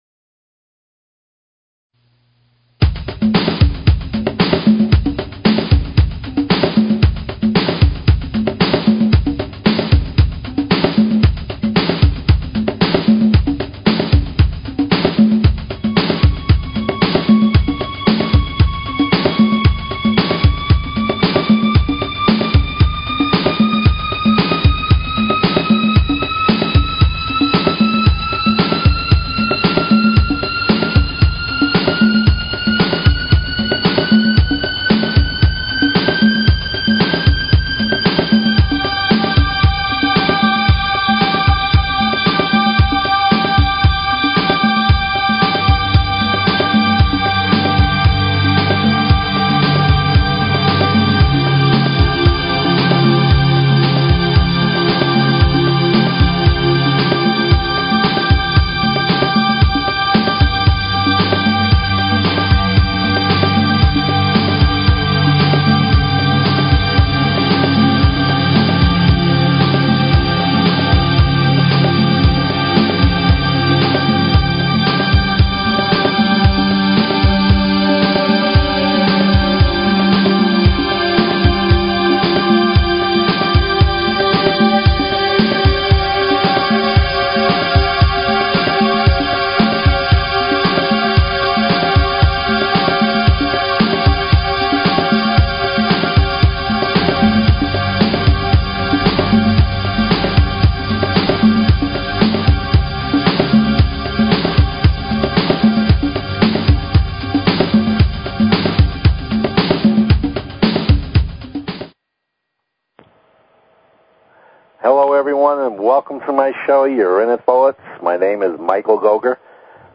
Talk Show Episode, Audio Podcast, Your_Inner_Thoughts and Courtesy of BBS Radio on , show guests , about , categorized as